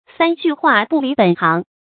成語拼音sān jù huà bù lí běn háng
三句話不離本行發音